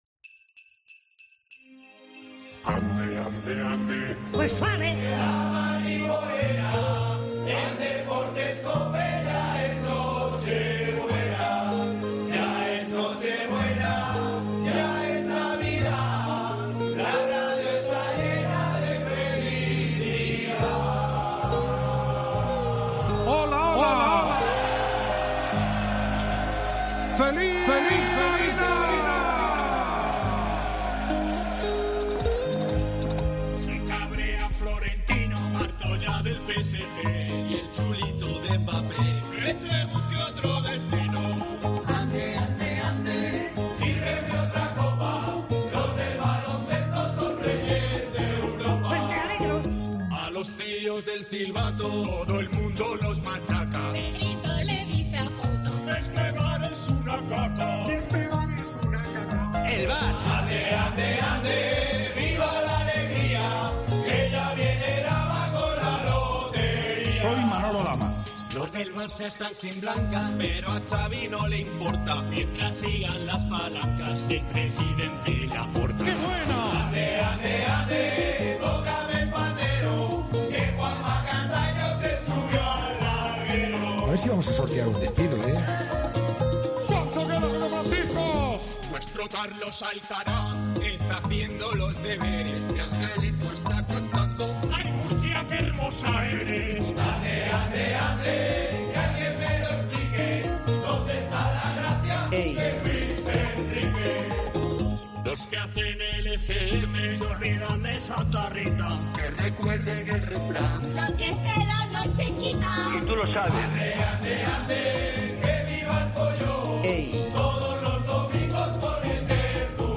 Llega ya la Navidad y como es tradición la redacción de Deportes COPE da la bienvenida a las fiestas con el tradicional villancico.